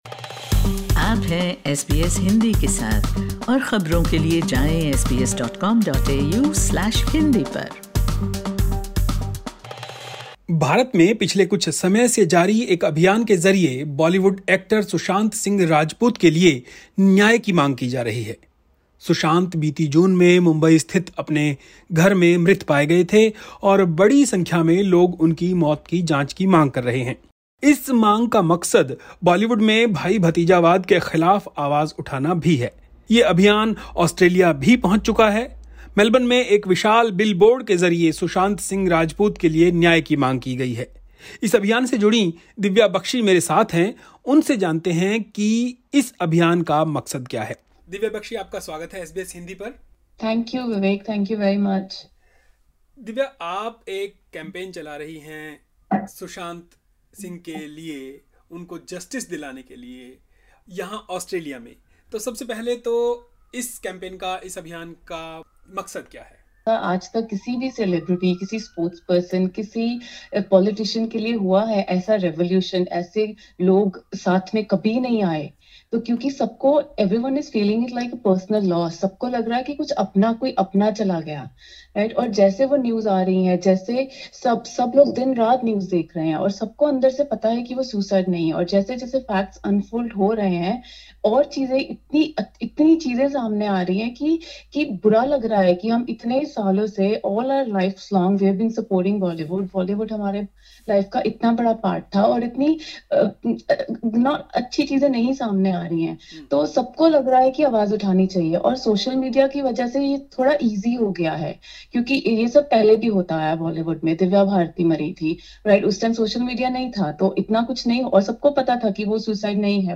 पूरी बातचीतः